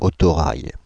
Ääntäminen
Ääntäminen Paris: IPA: [o.to.raj] Tuntematon aksentti: IPA: /o.to.ʁaj/ Haettu sana löytyi näillä lähdekielillä: ranska Käännös Substantiivit 1. motorvagono 2. traktbuso Suku: m .